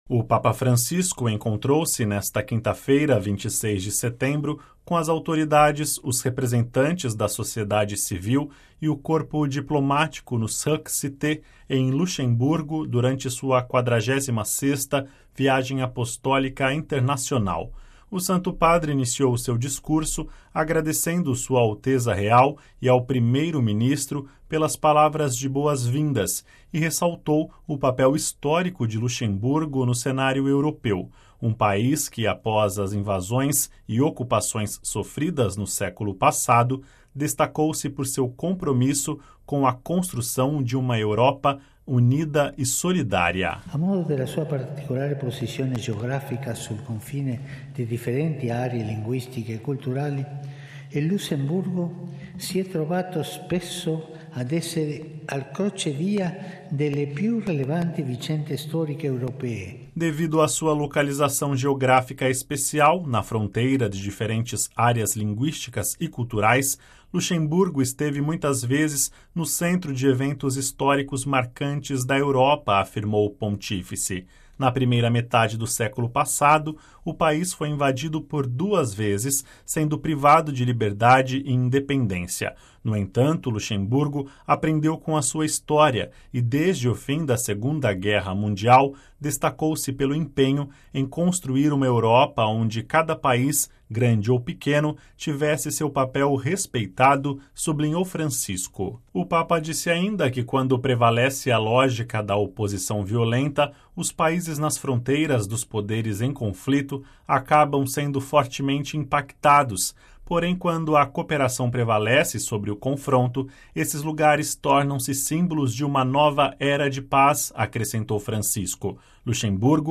O Papa Francisco, nesta quinta-feira (26/09), pronunciou o seu primeiro discurso em Luxemburgo durante o encontro com as autoridades, os representantes da sociedade civil e o Corpo Diplomático no Cercle Cité, no âmbito de sua 46ª Viagem Apostólica Internacional.
Ouça com a voz do Papa e compartilhe